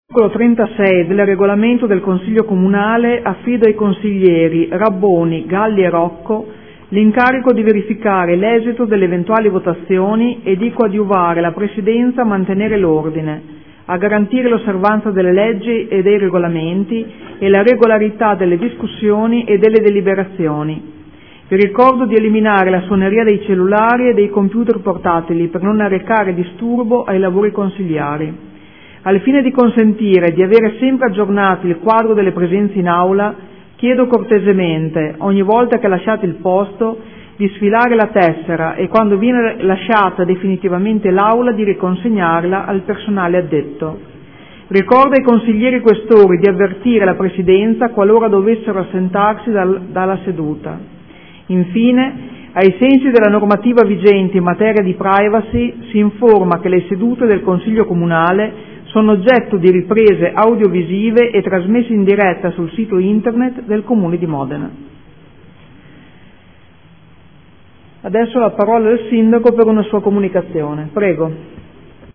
Seduta del 24/07/2014. La Presidente Francesca Maletti apre i lavori del Consiglio Comunale.